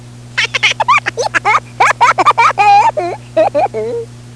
2-XL Laugh #2 95k
laugh02.wav